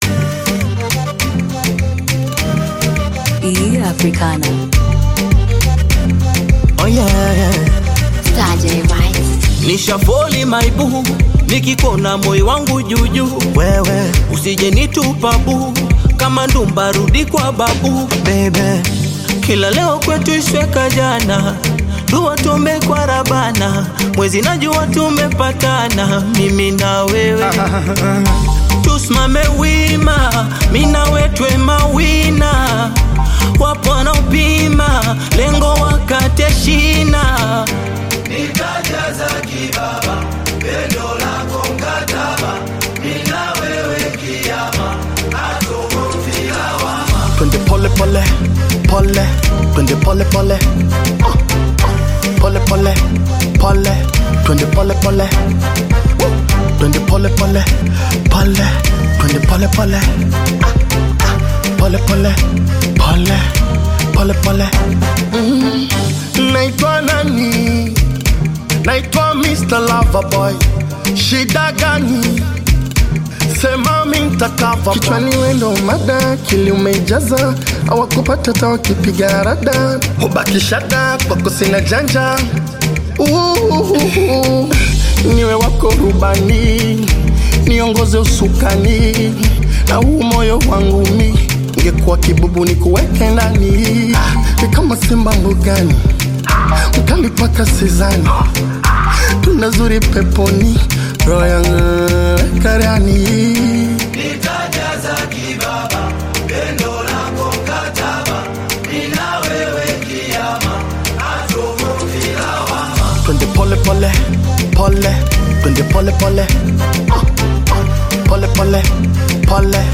Tanzanian Upcoming Bongo Flava artist, singer and songwriter
Bongo Flava